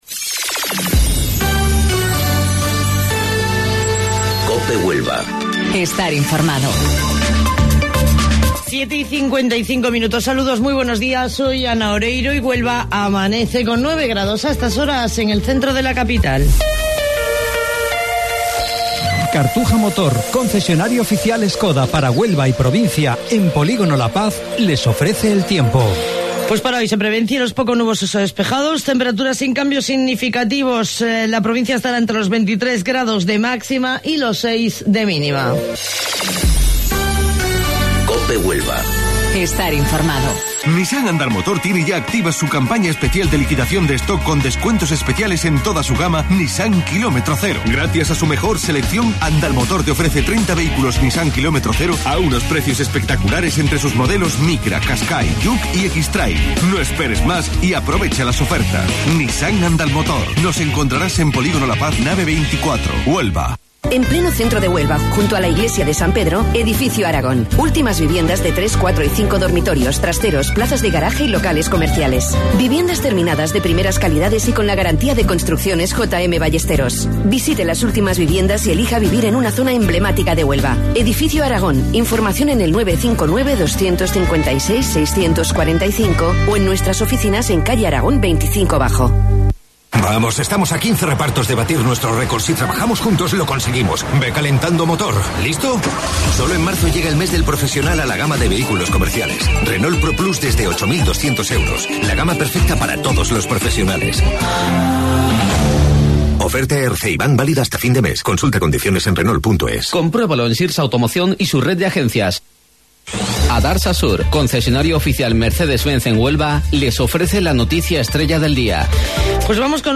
AUDIO: Informativo Local 07:55 del 22 de Marzo